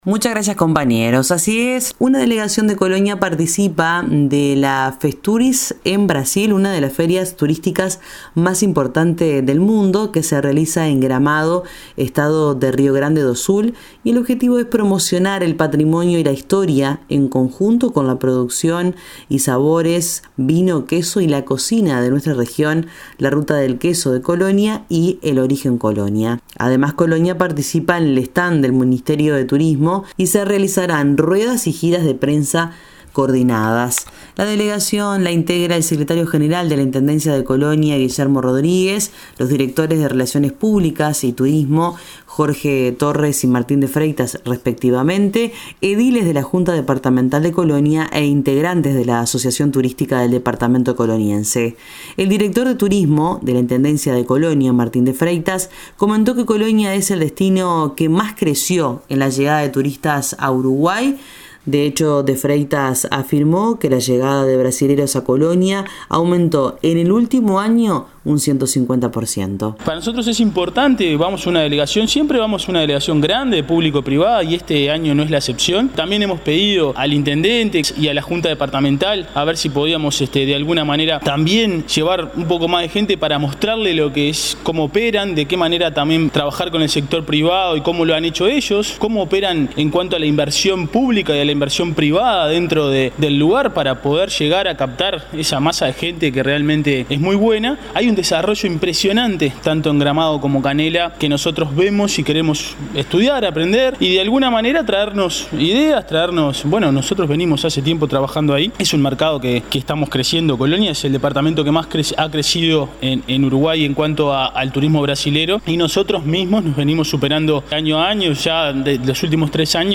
El director de Turismo de la Intendencia de Colonia, Martín de Freitas, comentó que Colonia es el destino que más creció en la llegada de turistas a Uruguay.